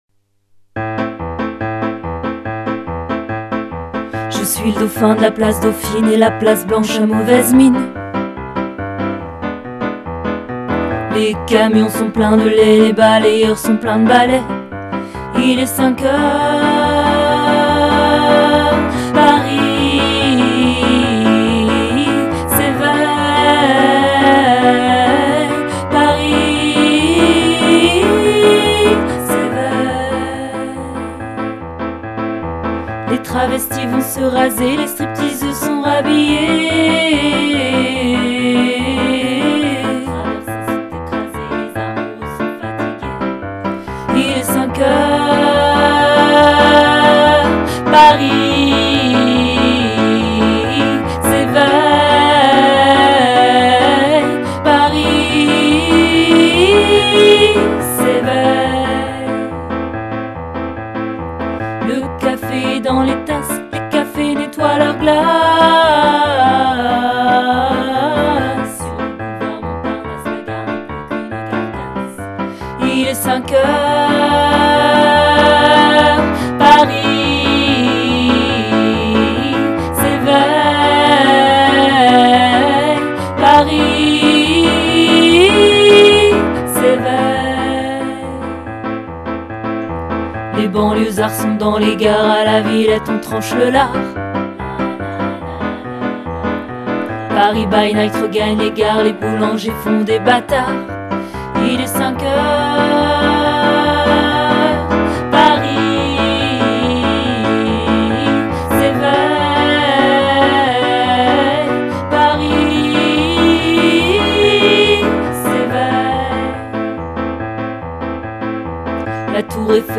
Hommes